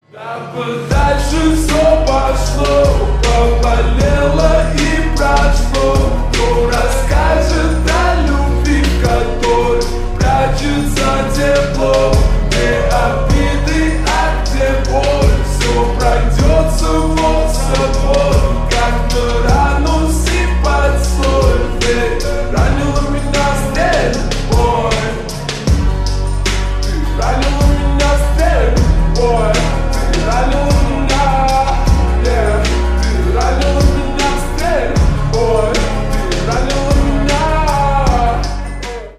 бесплатный рингтон в виде самого яркого фрагмента из песни
Ремикс # Поп Музыка